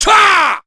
Chase-Vox_Attack4_kr.wav